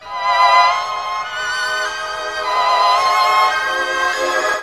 Index of /90_sSampleCDs/USB Soundscan vol.02 - Underground Hip Hop [AKAI] 1CD/Partition E/05-STRINGS
STRINGS 3P-R.wav